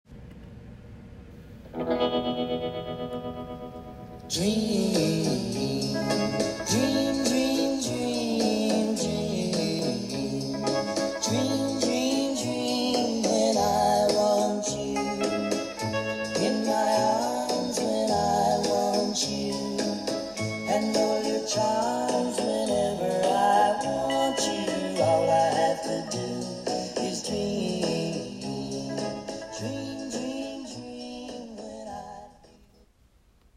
lovely, close harmonies